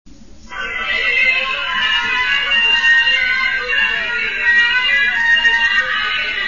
Chant nº3 des supporters 26 ko
supporters3.mp3